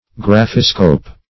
Graphiscope \Graph"i*scope\, n.